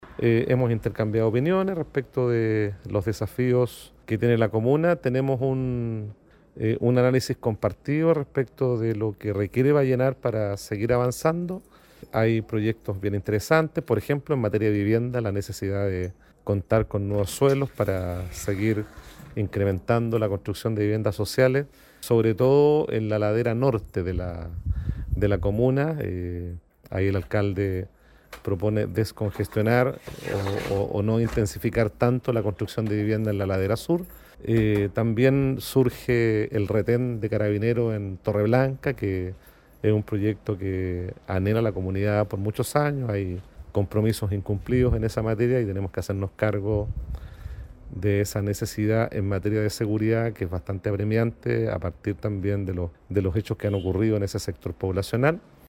CUNA-1-GOBERNADOR-VARGAS.mp3